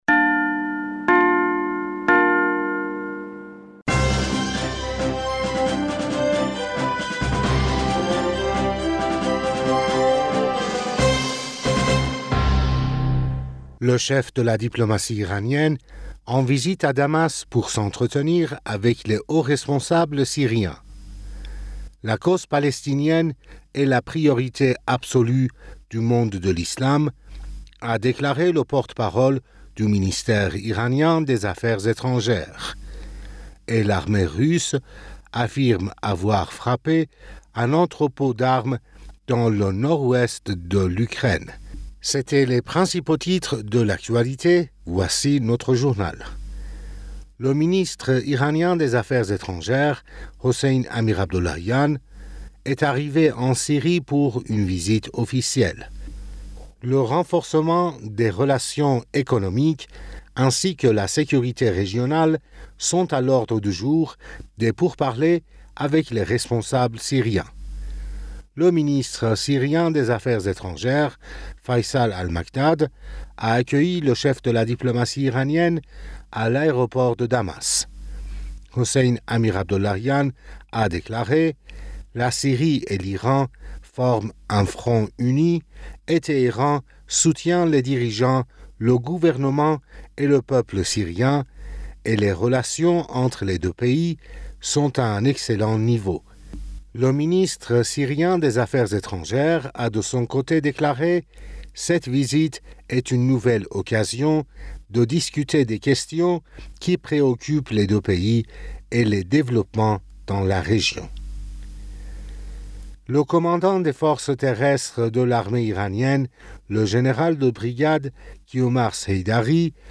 Bulletin d'information Du 23 Mars 2022